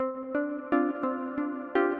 宽敞的合成器
描述：这是我为一首EDM歌曲的部分休息所做的循环。
Tag: 120 bpm House Loops Synth Loops 344.57 KB wav Key : Unknown